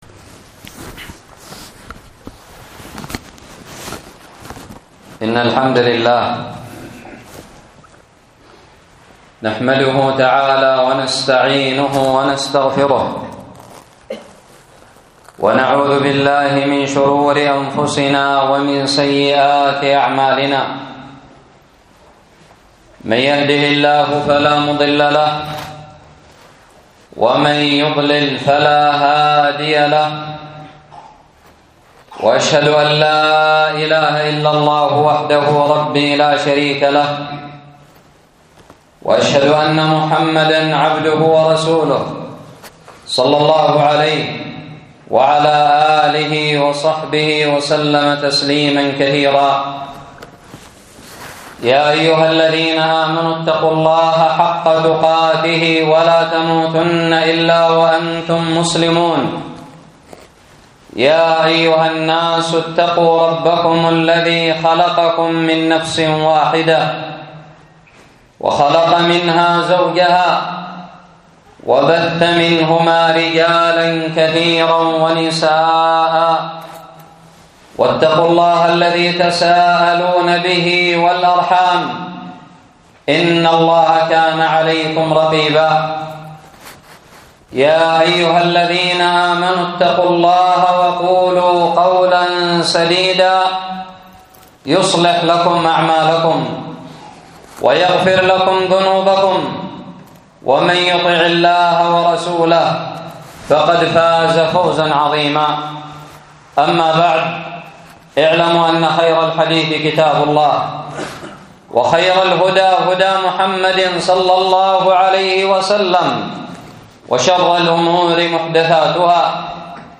خطب الجمعة
ألقيت بدار الحديث السلفية للعلوم الشرعية بالضالع في عام 1438هــ